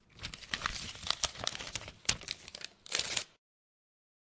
沙沙 "的纸张皱缩02
描述：我手里拿着一张纸。 用Tascam DR40录制。
Tag: 羊皮纸 弄皱 弄皱 沙沙 沙沙 处理 处理 皱巴巴